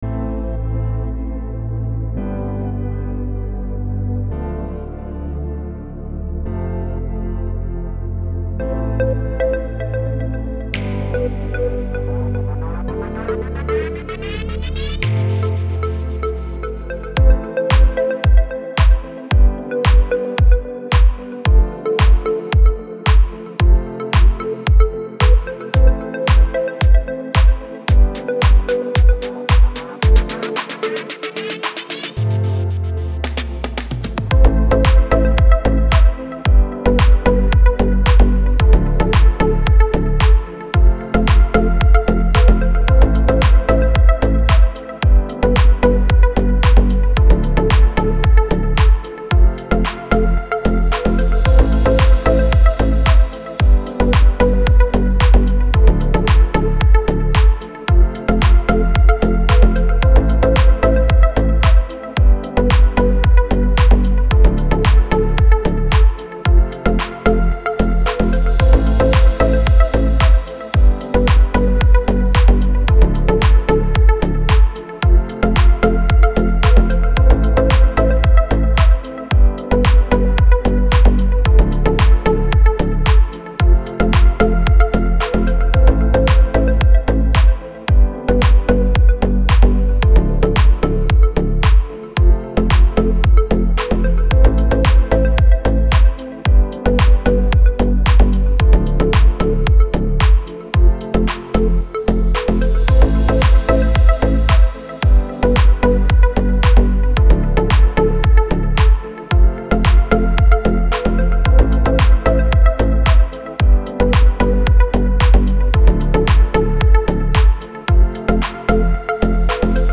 موسیقی بی کلام دیپ هاوس ریتمیک آرام